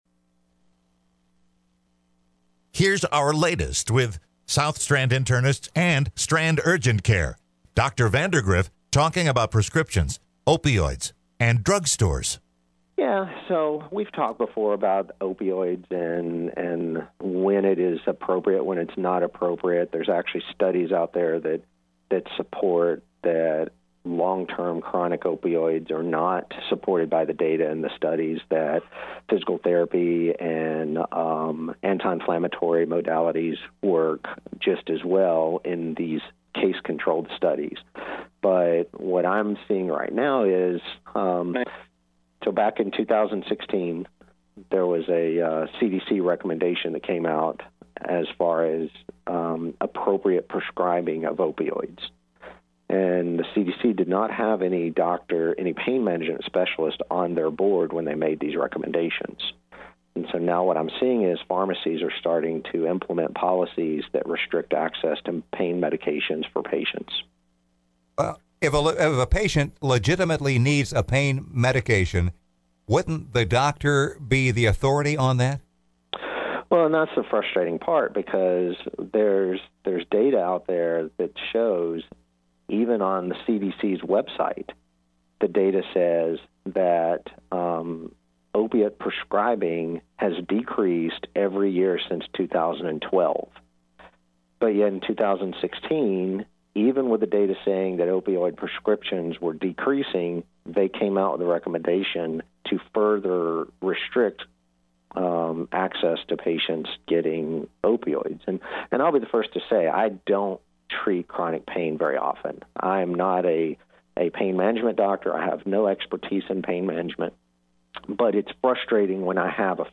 Today’s podcast was broadcast live on WEZV Radio.